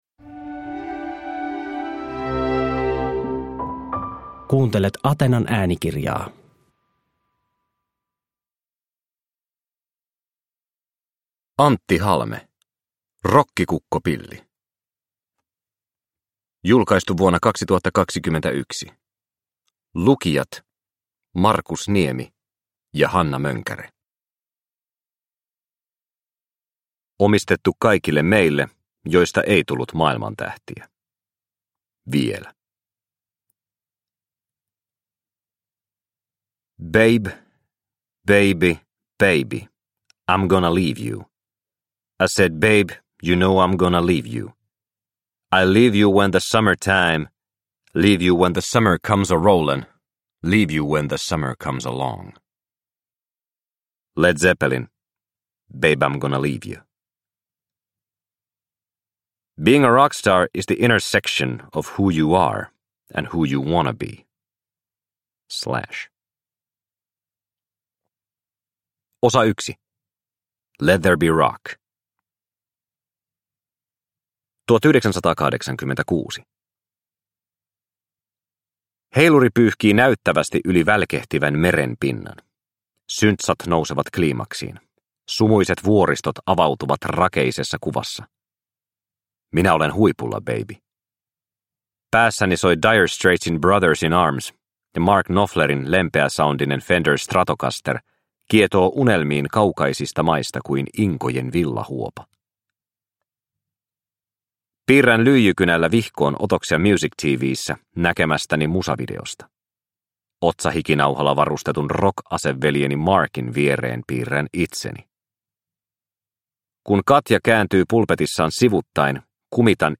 Rokkikukkopilli – Ljudbok